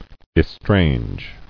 [es·trange]